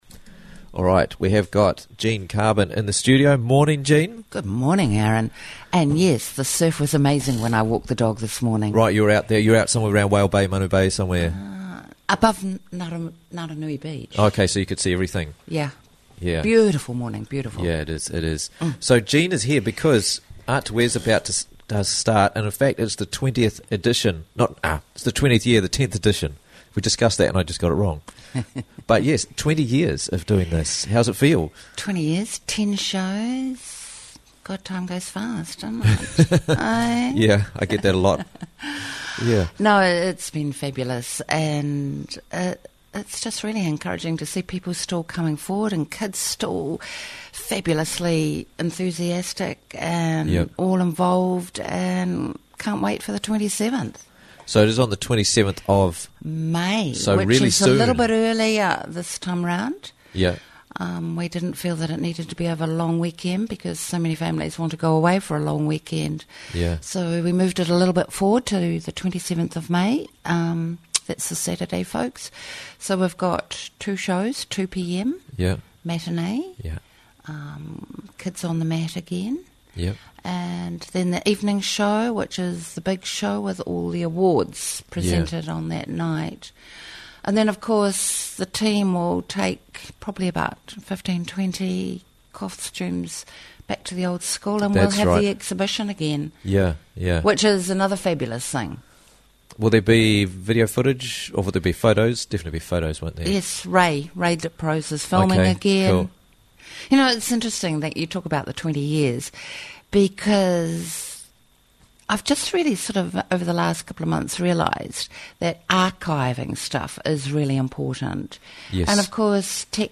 Quickly and easily listen to Interviews from the Raglan Morning Show for free!